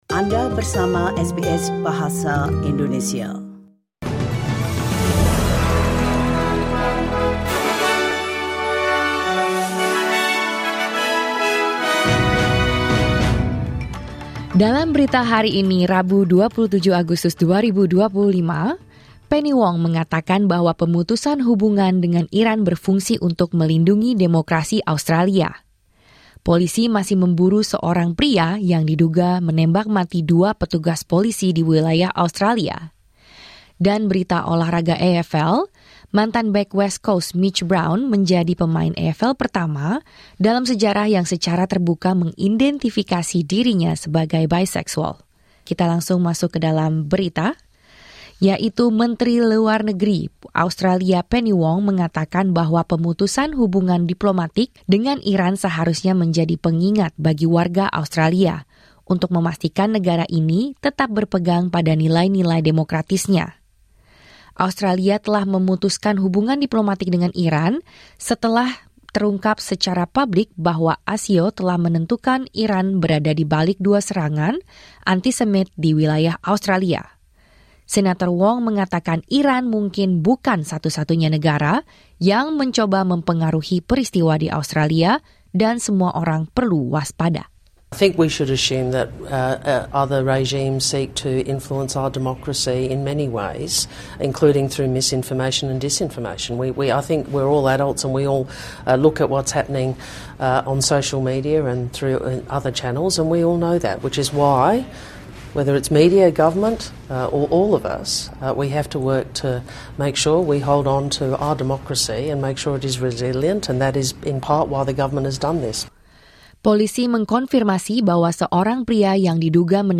Berita Terkini SBS Audio Program Bahasa Indonesia - 27 Agustus 2025
The latest news SBS Audio Indonesian Program – 27 August 2025.